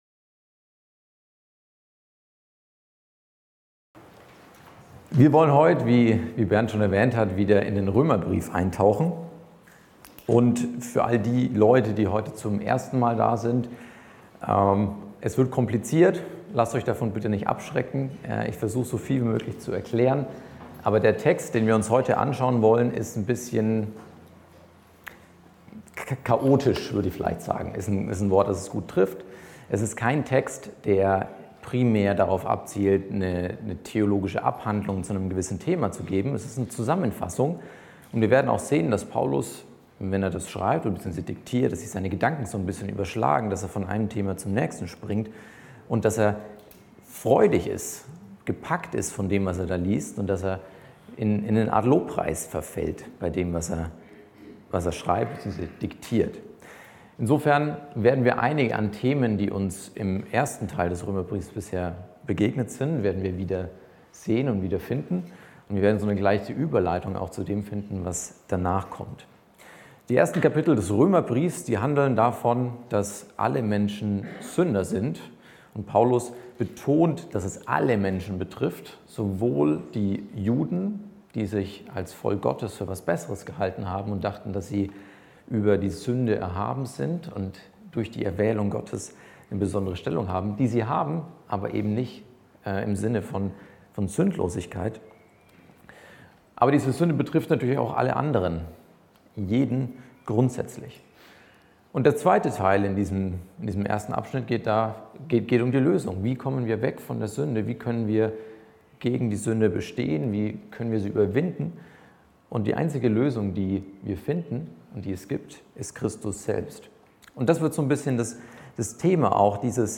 Heute predigte